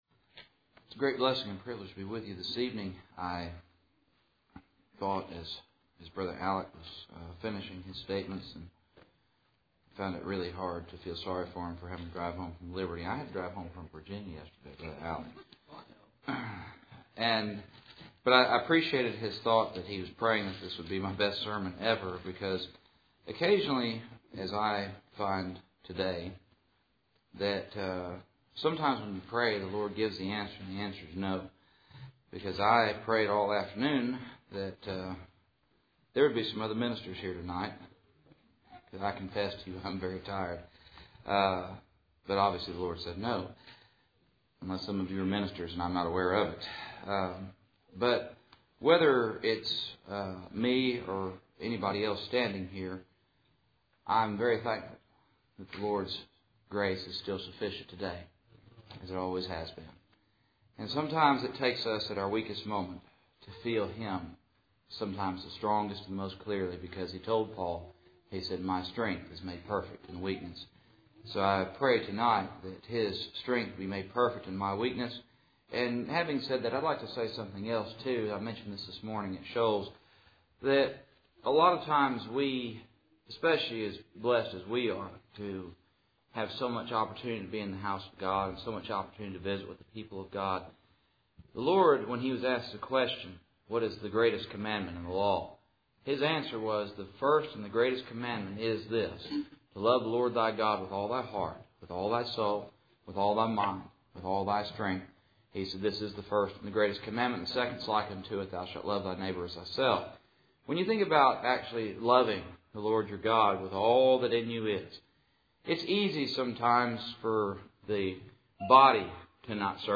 Passage: 1 Samuel 16:0 Service Type: Cool Springs PBC Sunday Evening